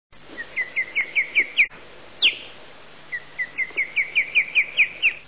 Rybołów - Pandion haliaetus
głosy